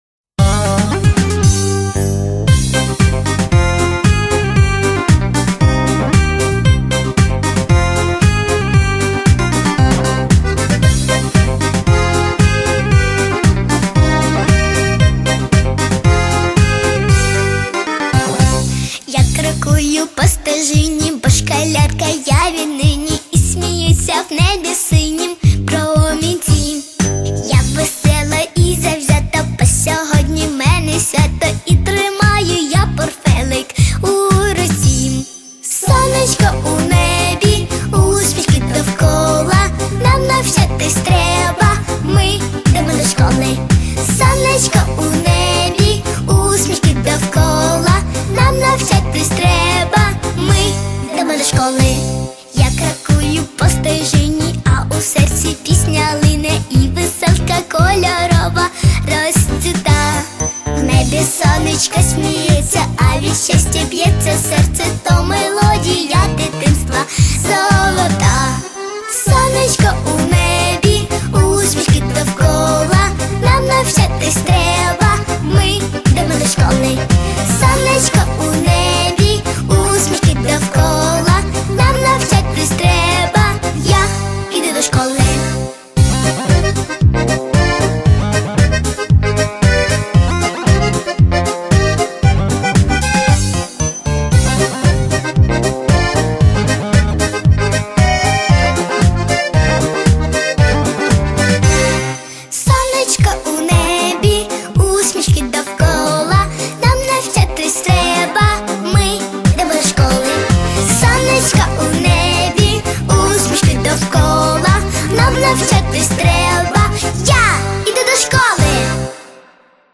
Помірна
Соло
Дитяча
Дитячі.